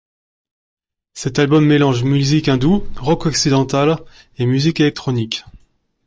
Pronúnciase como (IPA)
/ɔk.si.dɑ̃.tal/